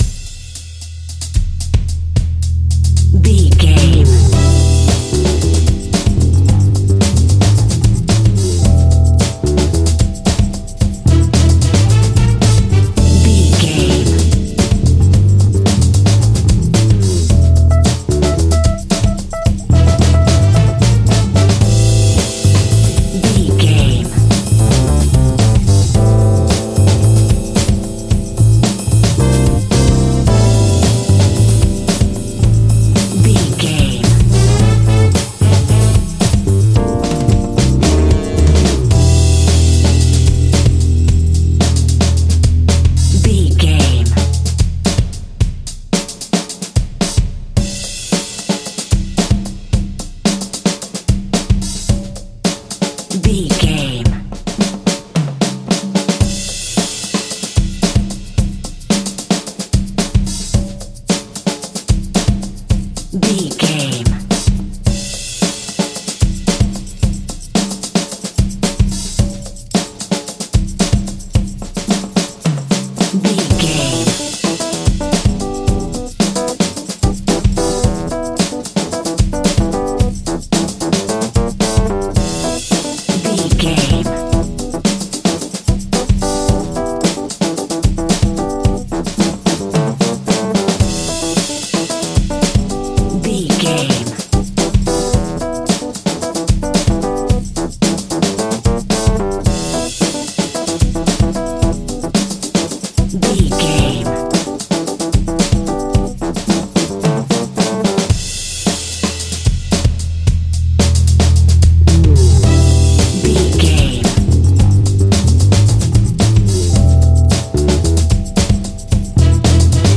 Aeolian/Minor
lively
electric guitar
electric organ
saxophone
percussion